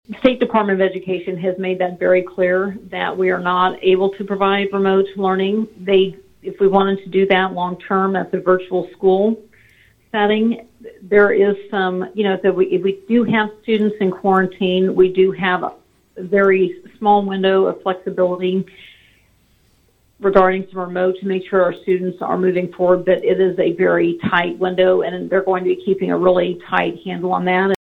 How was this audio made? School administrators were part of separate interviews on KVOE the past few days to update their situations.